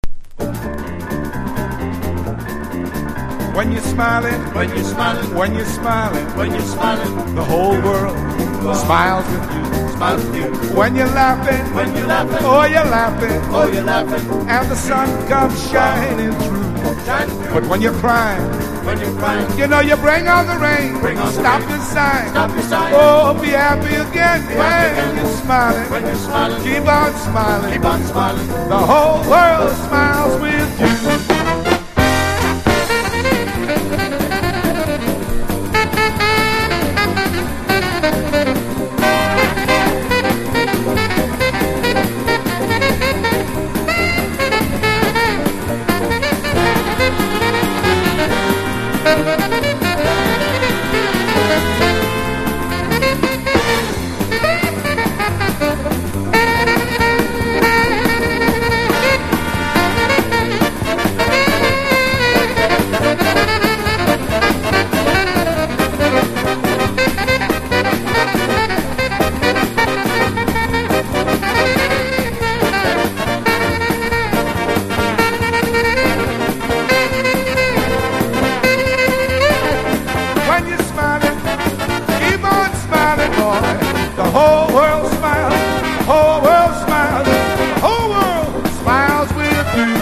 ルイ・プリーマの相棒としても知られる白人ジャンプ・サックス・プレイヤー＆シンガー。70年代のラスベガス録音盤。